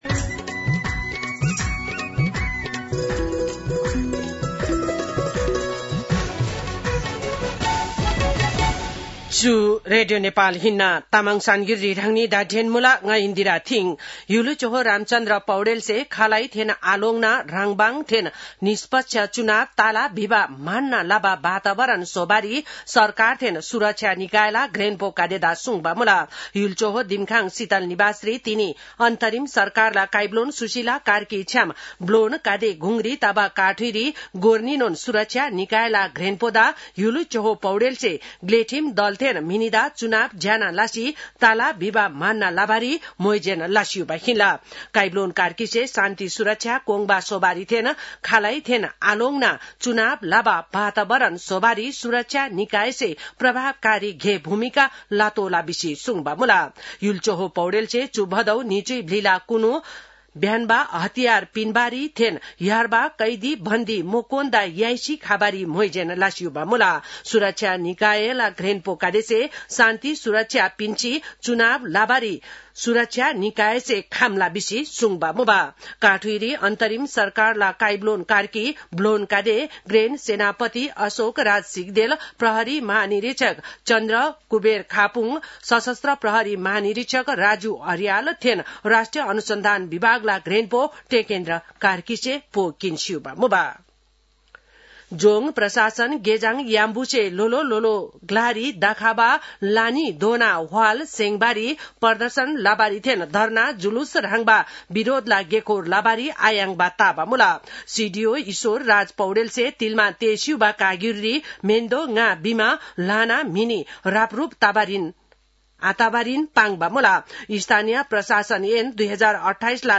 तामाङ भाषाको समाचार : १ कार्तिक , २०८२